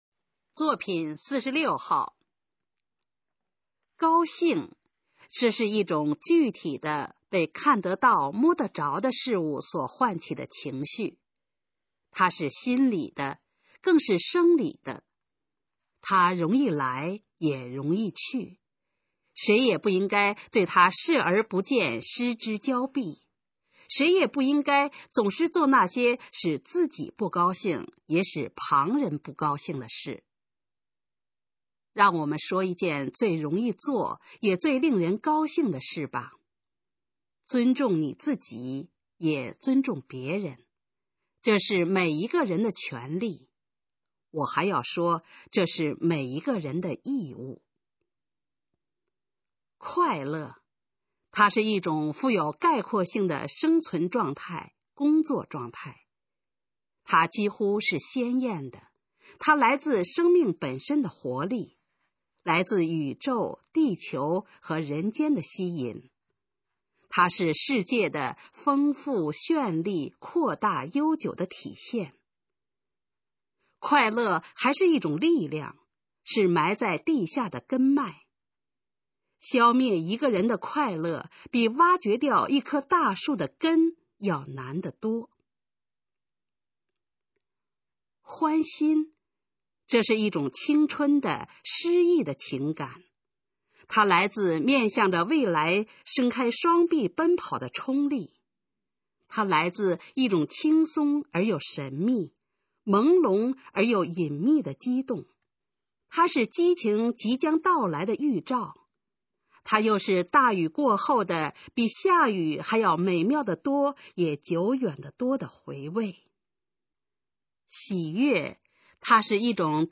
《喜悦》示范朗读_水平测试（等级考试）用60篇朗读作品范读　/ 佚名